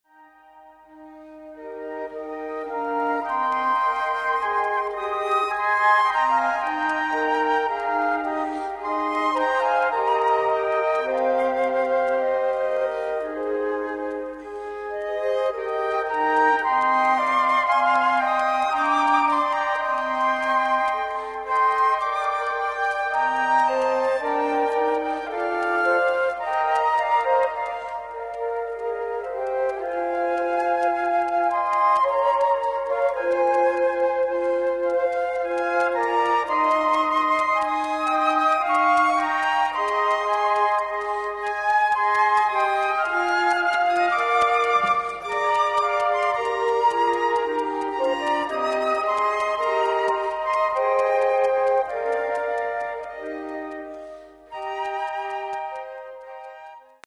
Bearbeitung für 4 Flöten